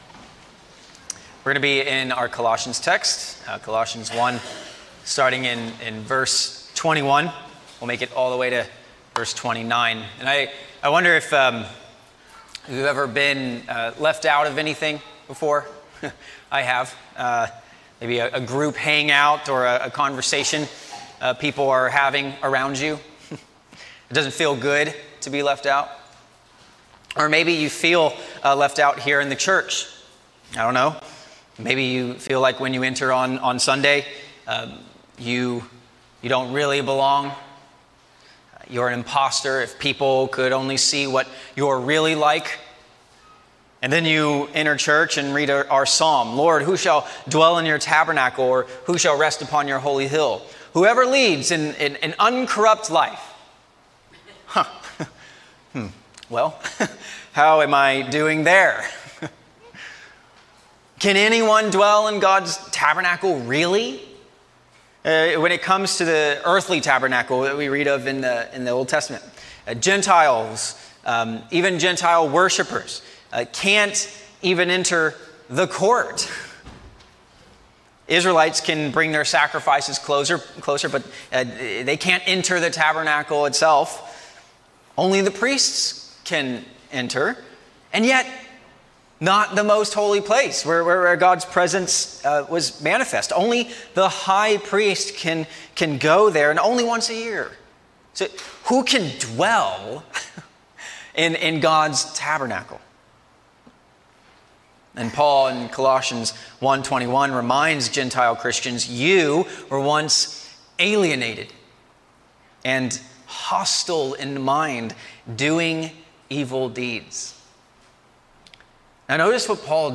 A sermon on Colossians 1:21-29